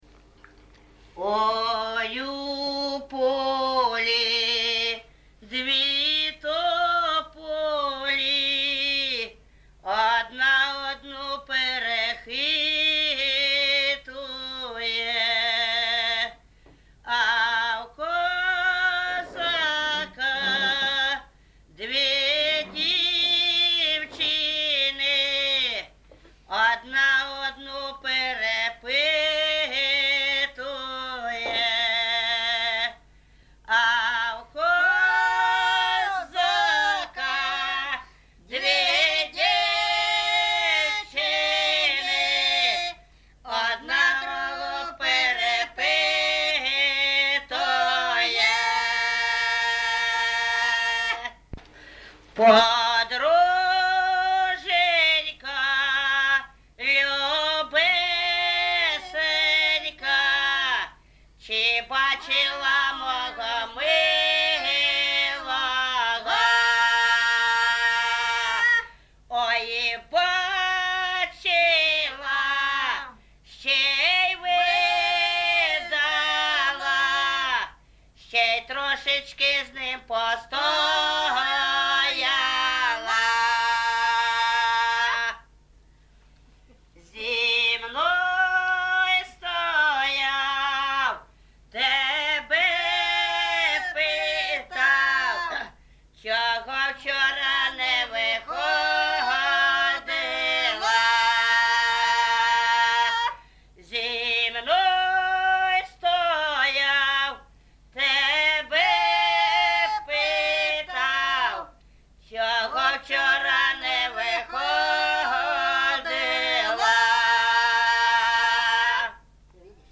ЖанрПісні з особистого та родинного життя
Місце записус. Гарбузівка, Сумський район, Сумська обл., Україна, Слобожанщина